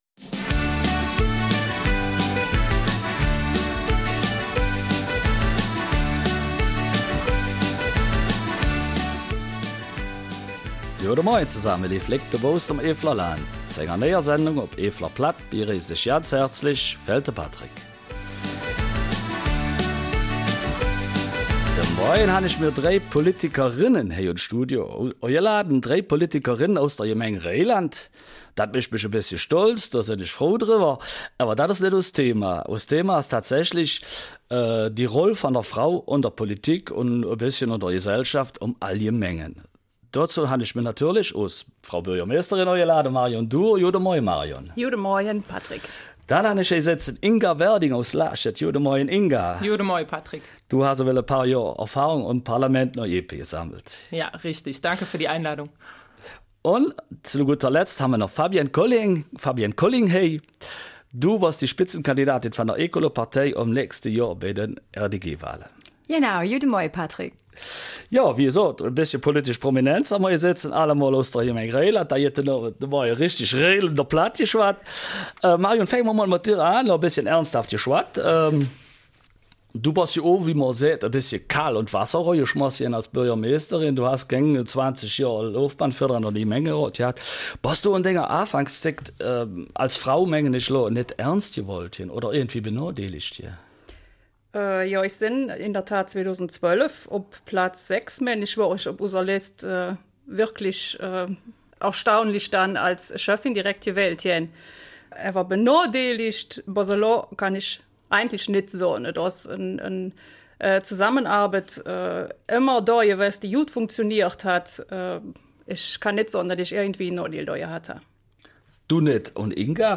Eifeler Mundart: Ihre Frau stehen in Politik und Gesellschaft
Studiogäste sind drei Politikerinnen aus der Gemeinde Burg-Reuland: Marion Dhur, Inga Werding und Fabienne Colling.